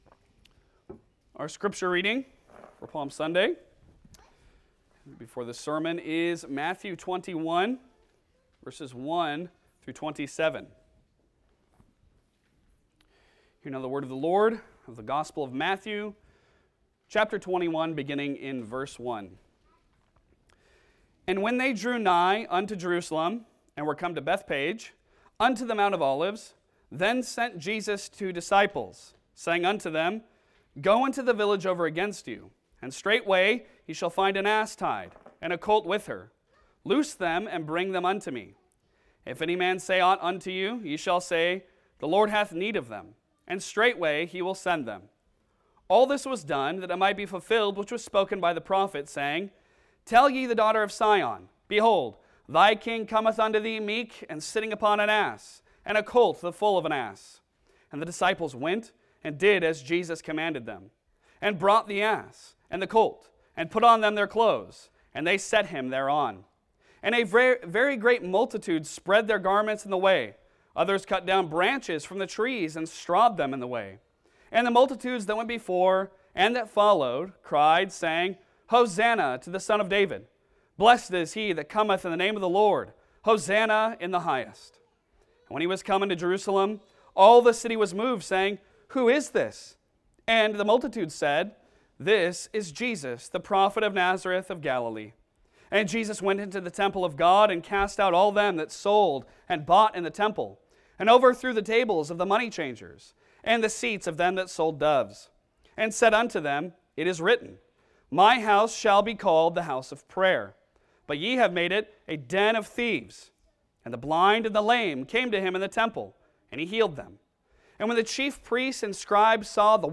Passage: Matthew 21:1-27 Service Type: Sunday Sermon